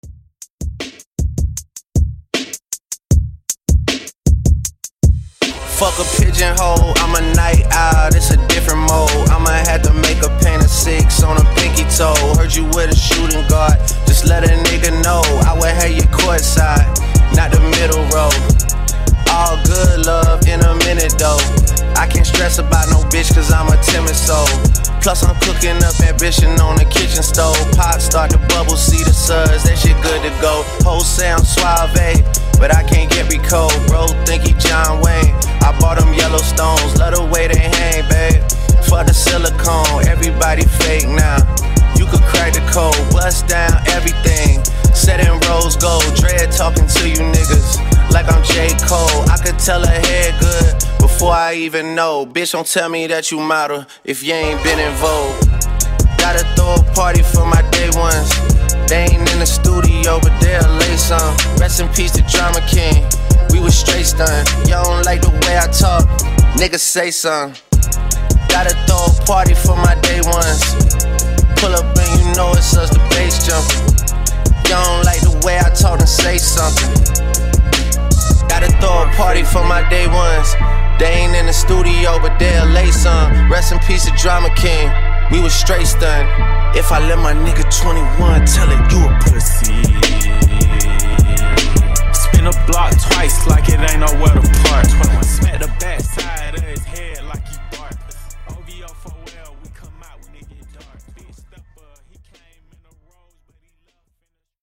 Genre: DANCE
Dirty BPM: 124 Time